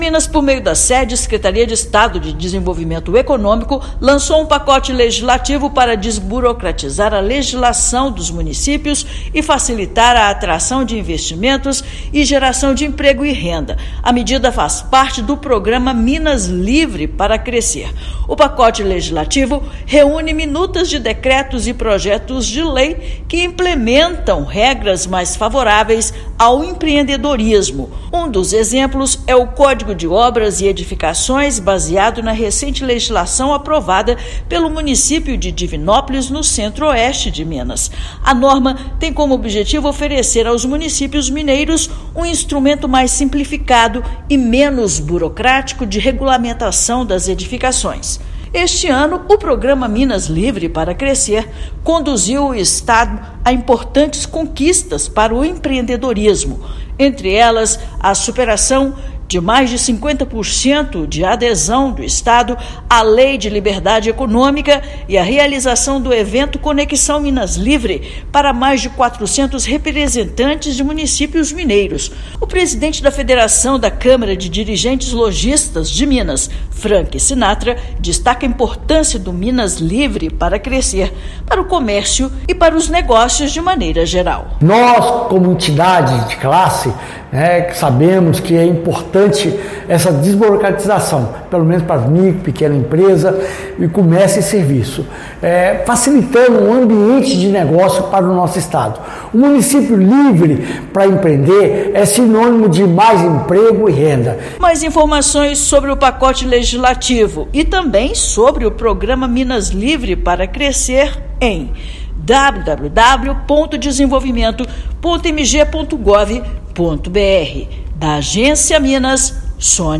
Ação integra o Minas Livre Para Crescer, programa da Sede-MG que mantém o estado com a maior adesão à legislação de Liberdade Econômica no país. Ouça matéria de rádio.